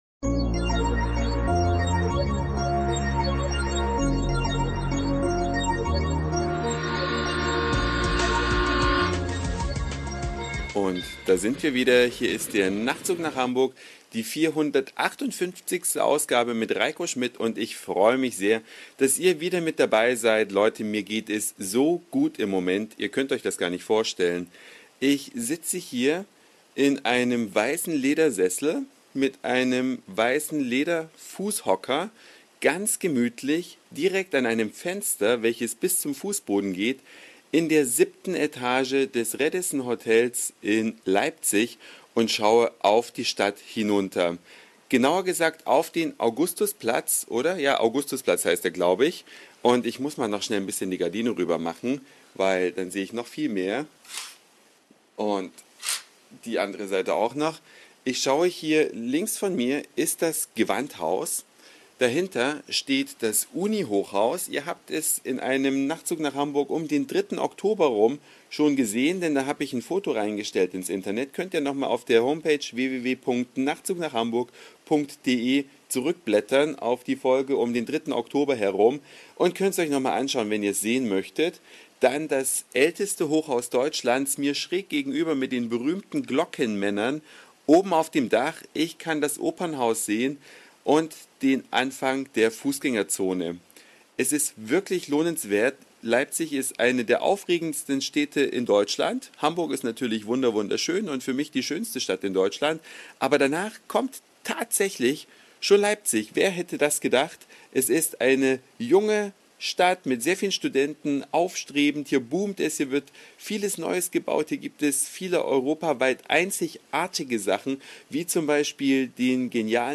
Eine Reise durch die Vielfalt aus Satire, Informationen, Soundseeing und Audioblog.
Innen ganz urig: Das Zelt mit Lagerfeuer innen